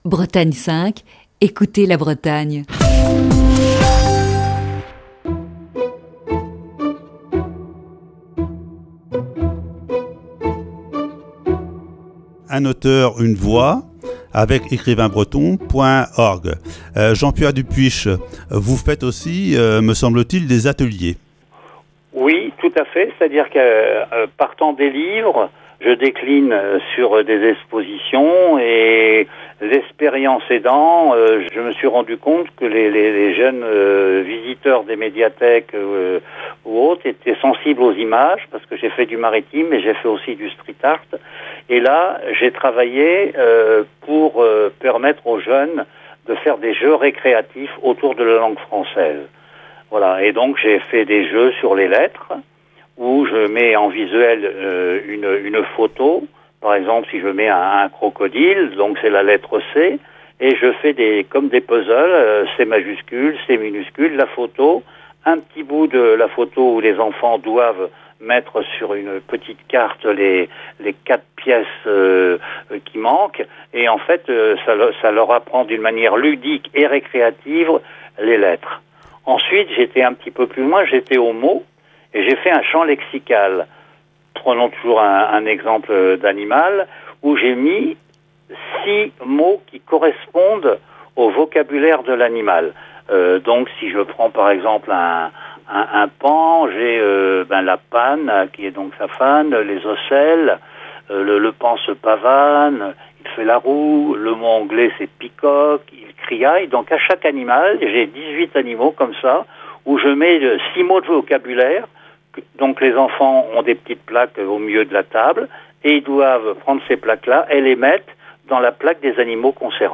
Chronique du 18 juin 2020.